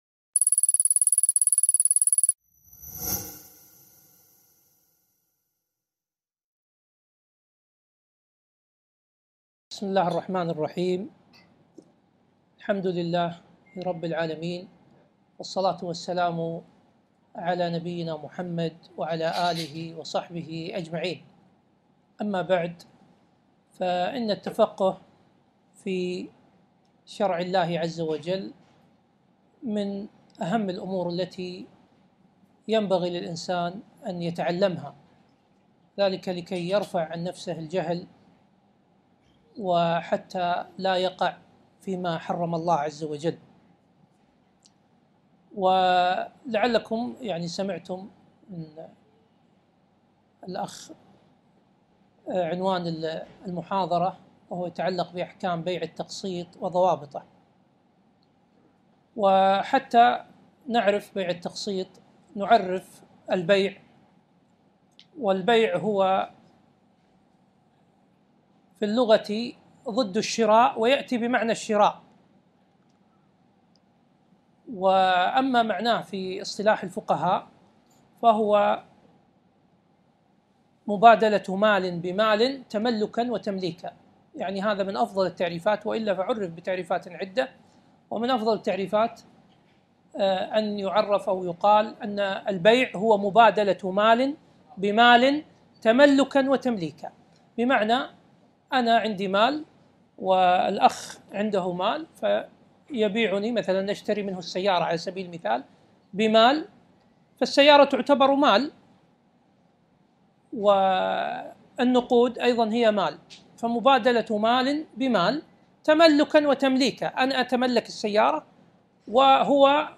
محاضرة - أحكام بيع التقسيط و ضوابطه